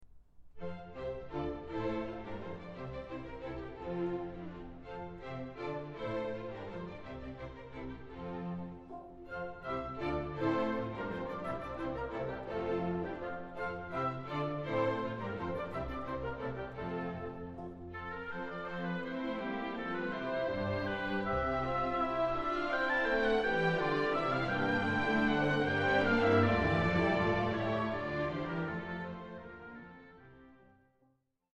Ballet Music